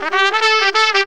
HORN RIFF 9.wav